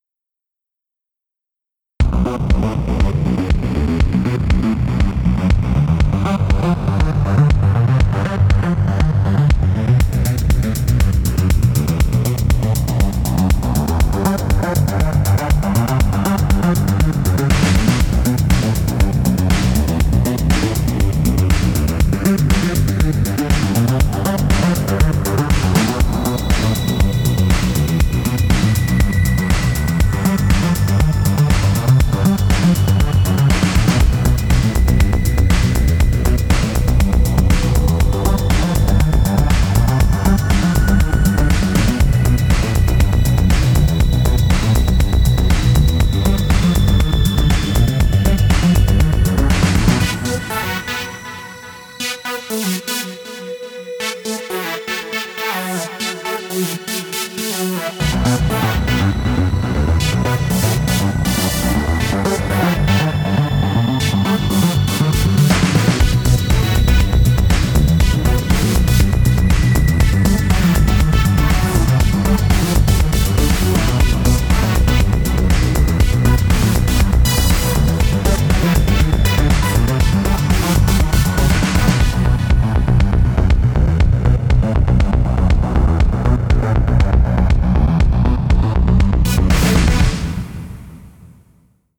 New Era. Транс - это жизнь!